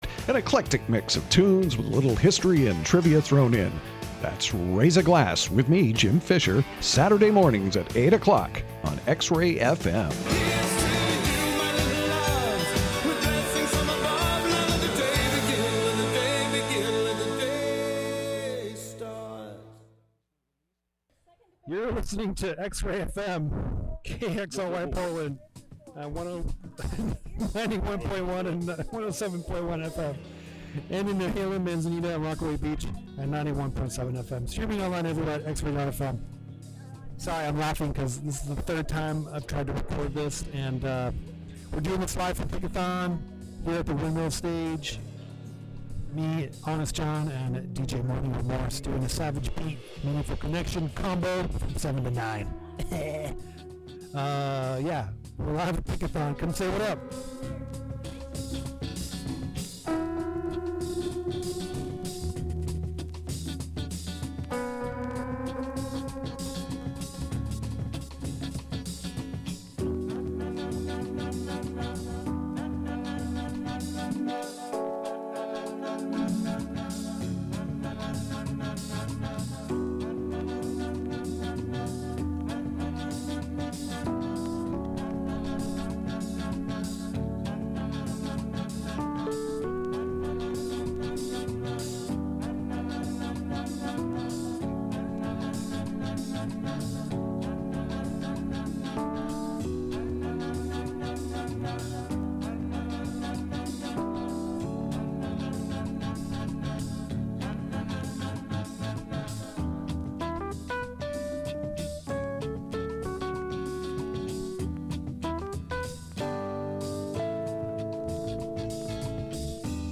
SAVAGE BEAT is garage, surf, proto-punk and a little more of the like plus some out of character stuff from time to time! Weirdo screamers and trashy creepers.